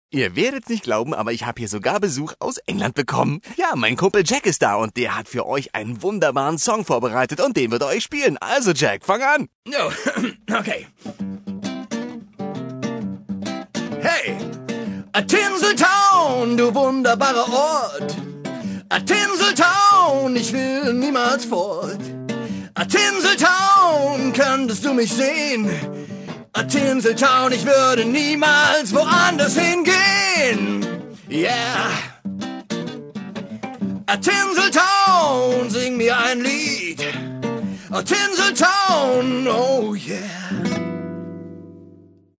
In addition to these in-game texts many audio files were recorded that give the player useful hints and information about recent news. Furthermore, there are 11 different radio DJs to lighten the "daily grind" in the moviemaking industry. Depending on the in-game date, patriotic paroles, cool chat and esoteric soul comfort sound through the air.